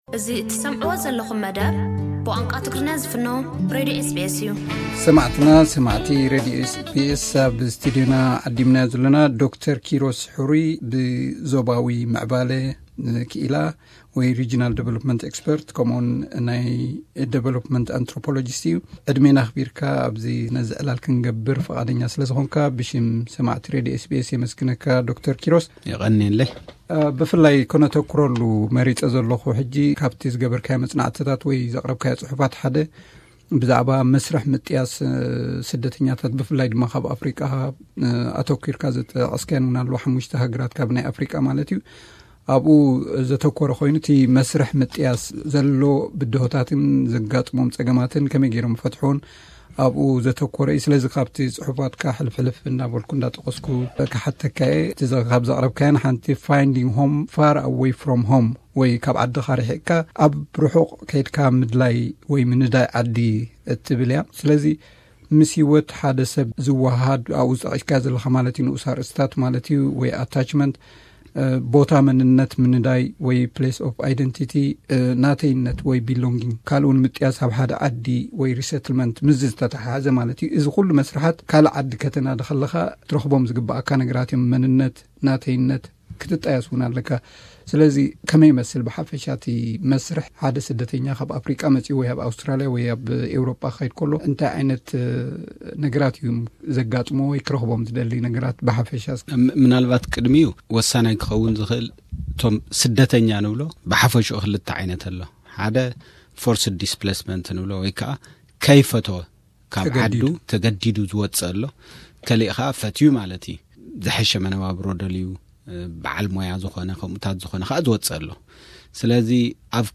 ብዛዕባዚ ዕድላትን ግድላትን መስርሕ ምጥያስ ህይወት ኣፍሪቃዊያን ስደተኛታት ኣብ ወጻኢ ሃገራትን ሰፊሕ ሙሁራዊ ትንታነ ሂቡና ኣሎ። ምሉእ ትሕዝቶ ናይቲ ቃለ መሕትት ኣብዚ ብምጥዋቕ ክስማዕ ይከኣል እዩ።